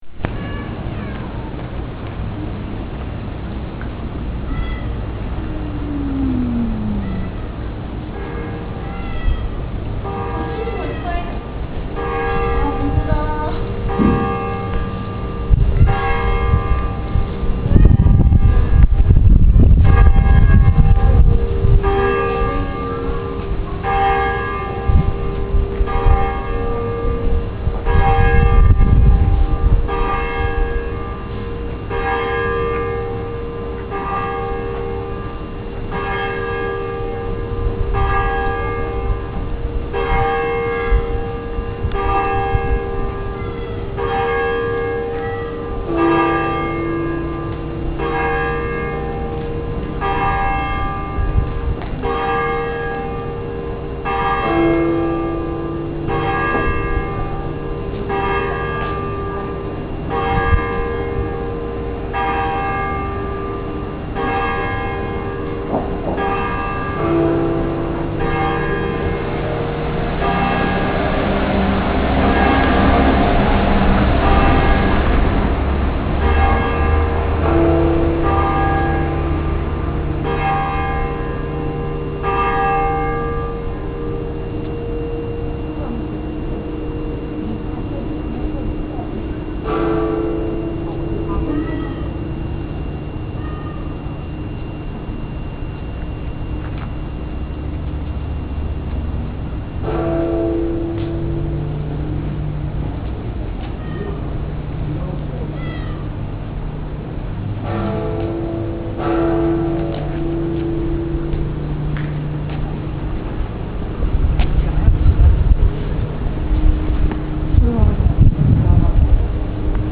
正午と午後6時に美しい鐘が辺り一帯に鳴り響く。また、夕刻は、すぐ側の寺院、妙行寺の鐘の音と交じり合い、音によって長崎の異国情緒を感じることができ、特におすすめだ。
♪ 大浦天主堂と妙行寺の鐘